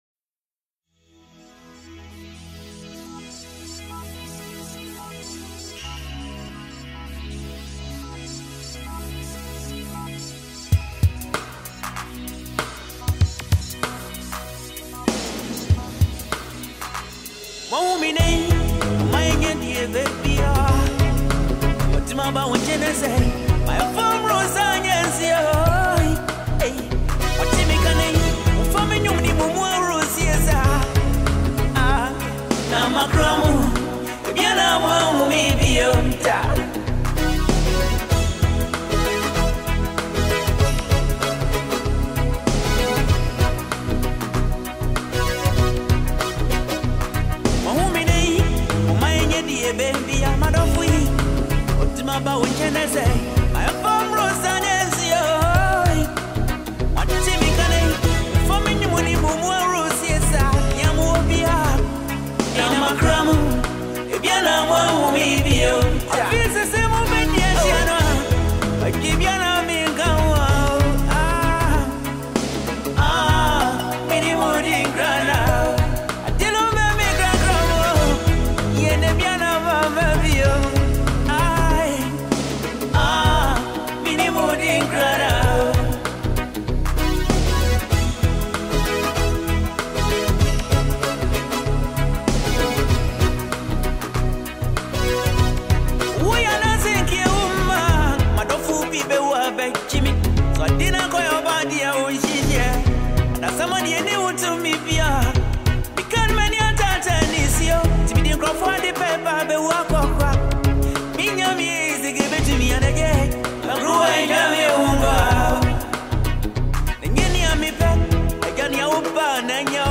Ghanaian highlife
With its blend of smooth rhythm, relatable lyrics
old-school highlife